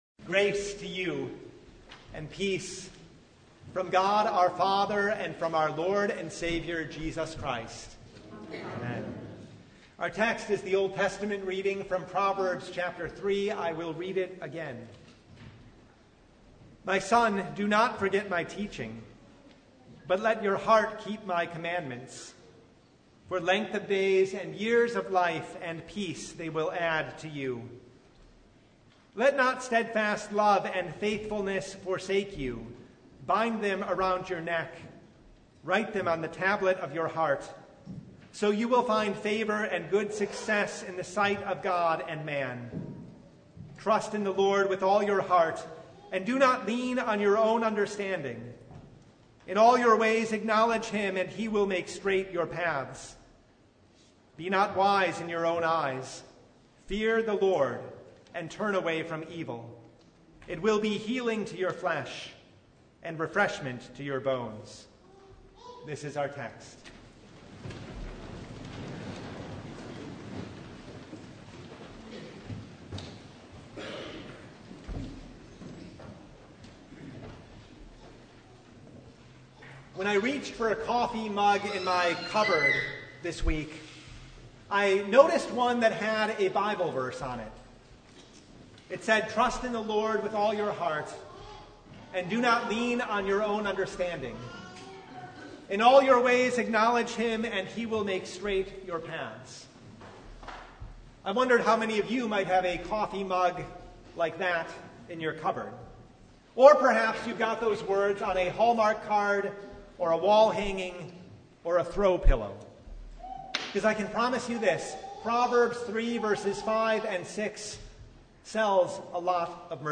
Proverbs 3:1-8 Service Type: The Feast of St. Bartholomew, Apostle Trust in the Lord.